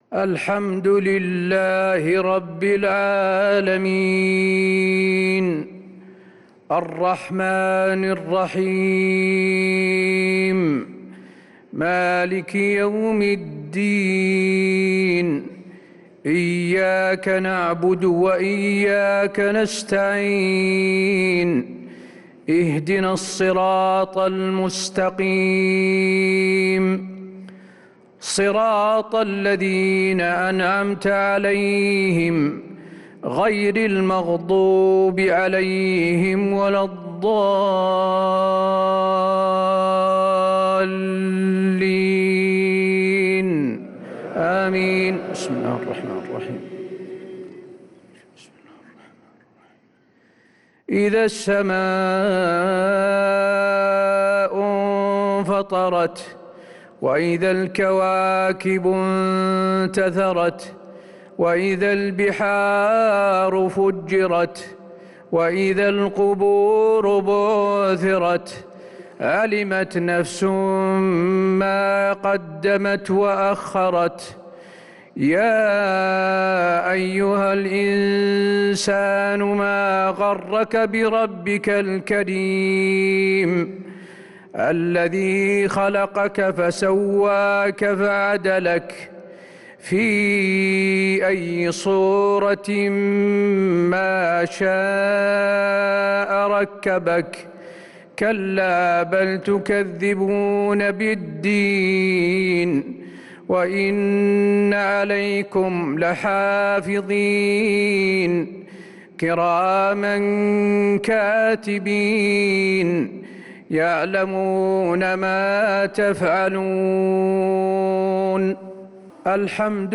عشاء الخميس 6-9-1446هـ سورة الإنفطار كاملة | Isha prayer Surat al-Infitar 6-3-2025 > 1446 🕌 > الفروض - تلاوات الحرمين